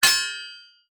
Sword Hit D.wav